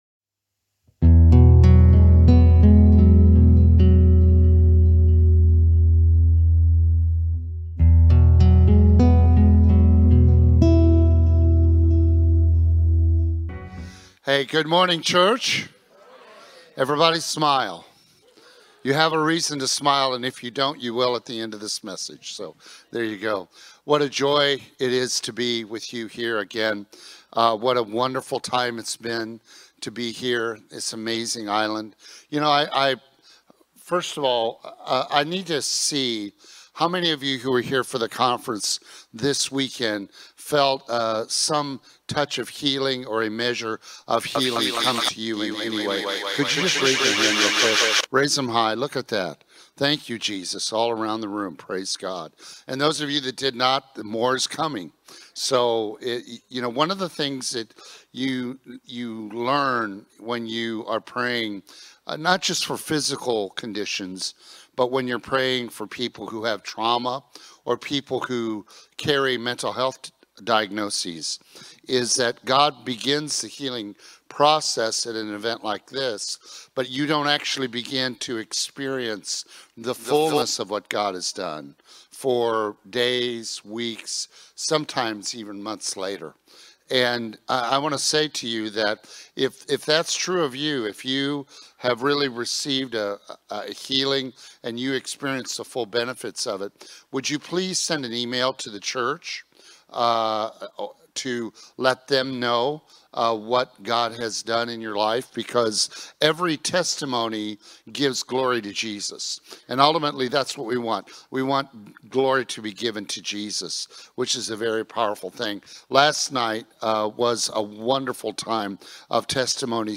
From Sunday sermons to Monday conversations, SCC Weekly offers real talk, honest questions, and encouragement for the week ahead. It’s all about growing in faith, walking with Jesus, and staying connected to what God’s doing in and through our community.